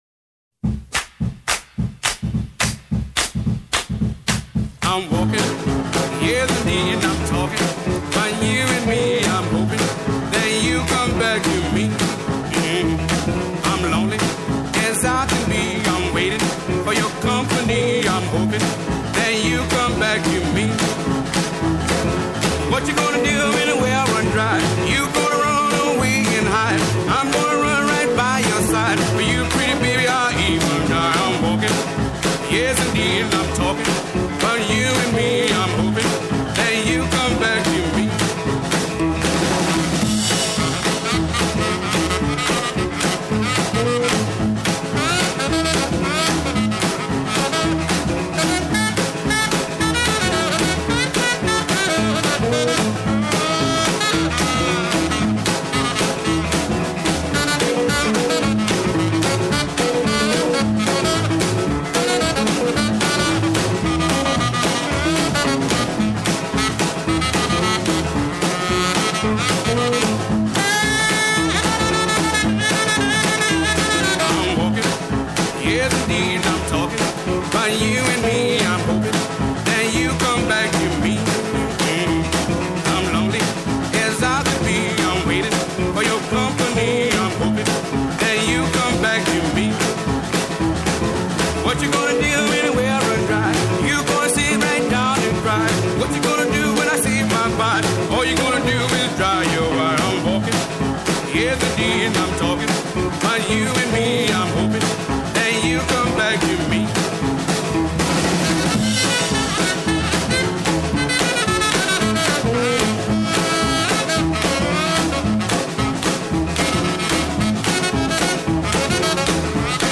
Great Grooves from the History of R&B
bpm 224
Second-Line Feel